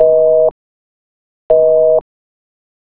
call_waiting.wav